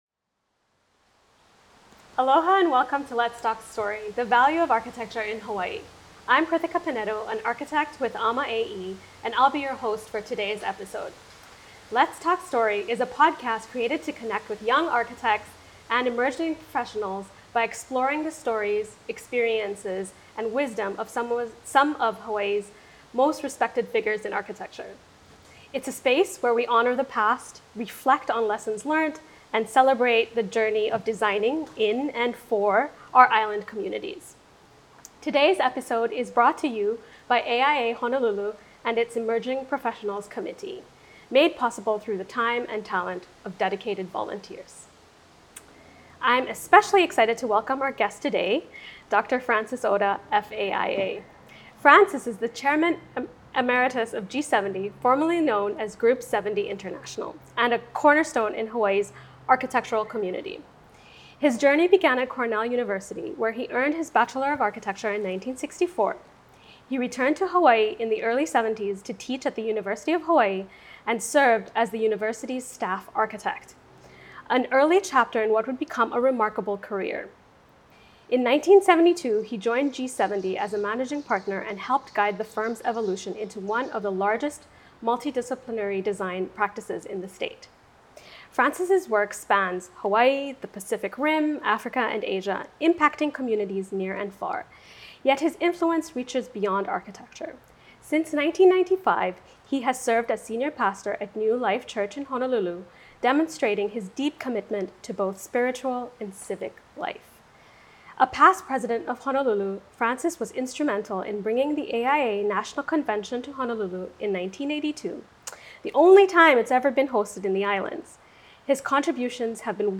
Biannual podcast and video releases exploring the value of architecture in Hawaii. Conversations facilitated between emerging architects and Fellows of the Institute.